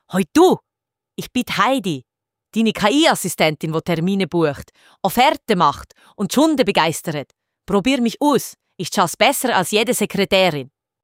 OKHeidi — Die Schweizer KI-Telefonassistentin die Termine bucht, Offerten erstellt, CRM updatet und echtes Schweizerdeutsch redet. 90% weniger verpassti Aaruef.
heidi_greeting.mp3